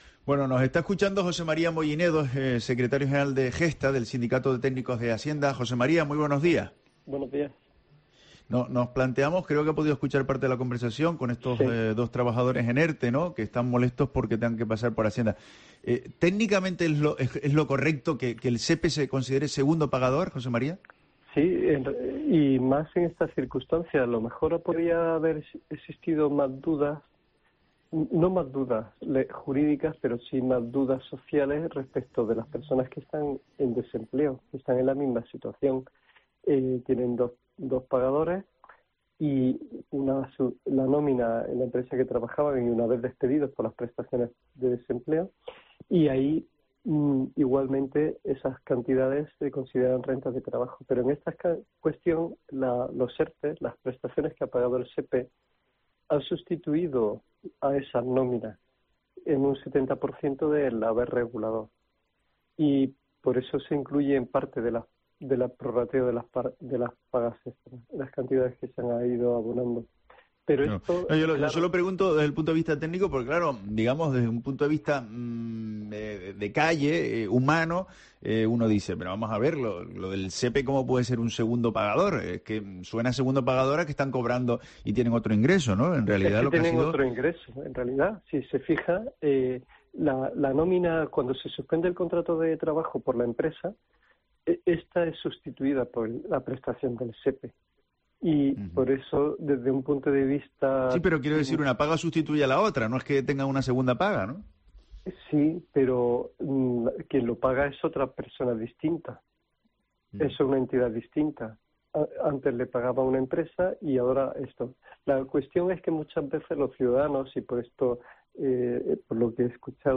ha resuelto en los micrófonos de COPE Canarias algunas de las principales dudas que se presentan este año ante la apertura de la campaña para presentar la declaración de la Renta.